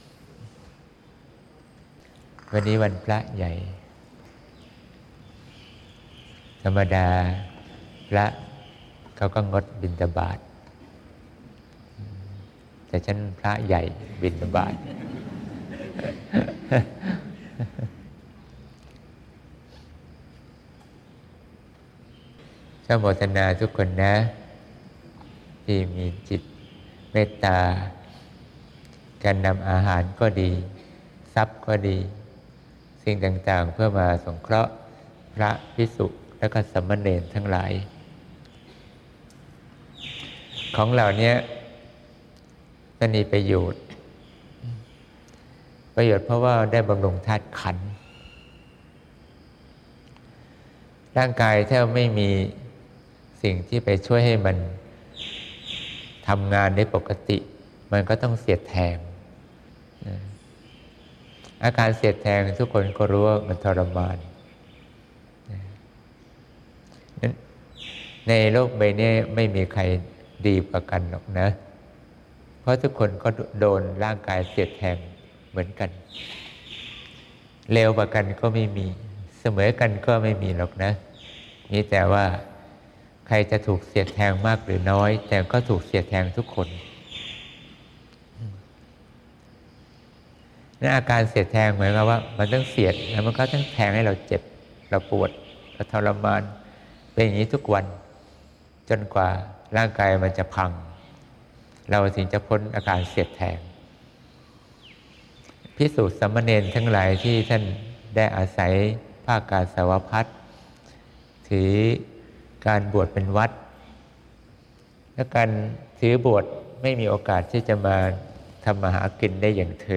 เสียงธรรม